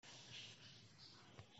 2025-03-24 17:01:23 Gênero: Rap Views